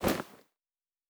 Bag 04.wav